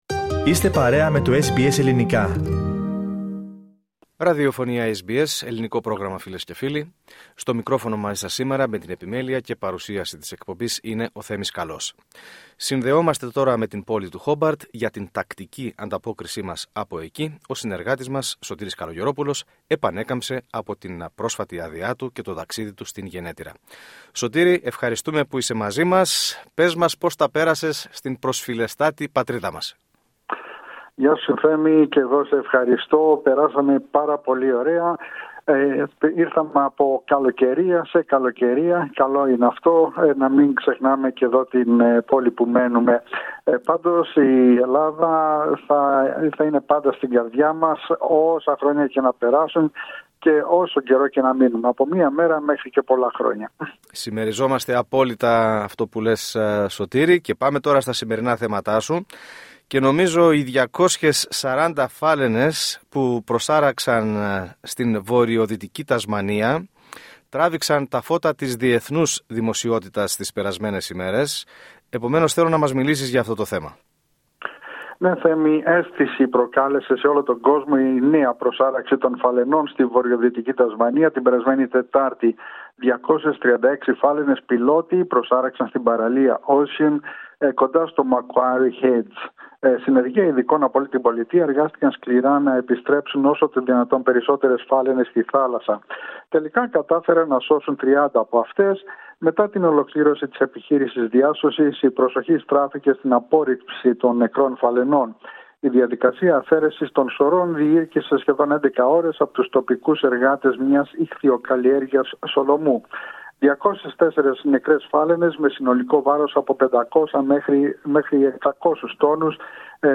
Ακούστε ολόκληρη την ανταπόκριση